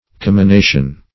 Commination \Com`mi*na"tion\, n. [L. comminatio, from comminari